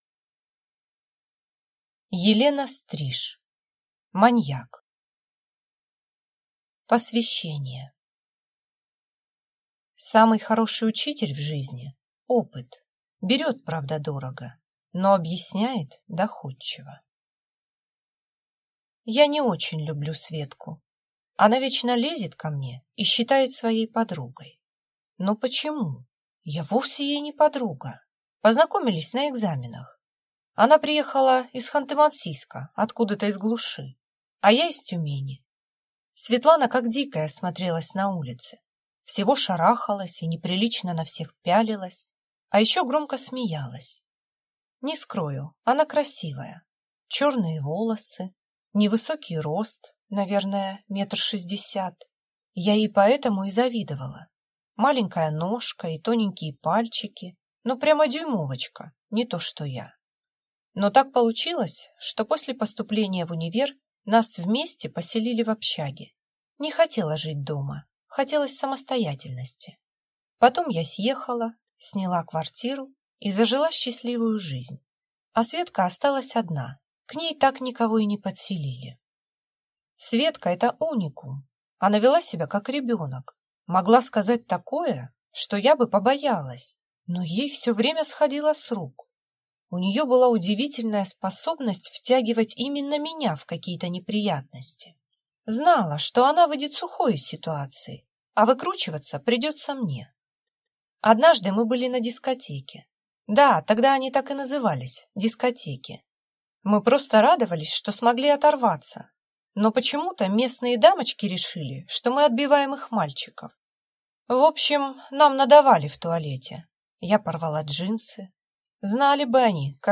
Aудиокнига